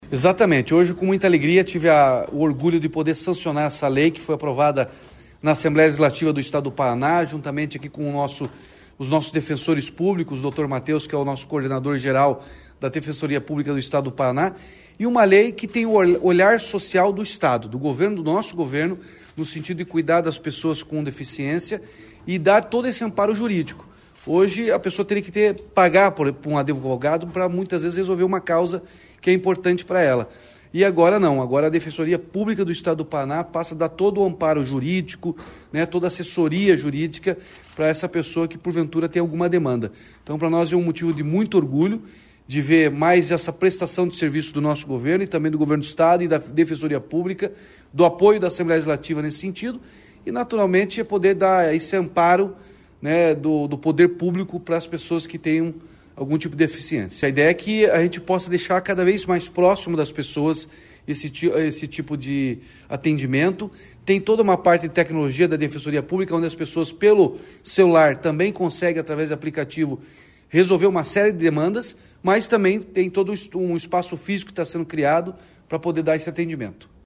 Sonora do governador Ratinho Junior sobre o sancionamento da lei que garante atendimento gratuito da Defensoria Pública para as PcDs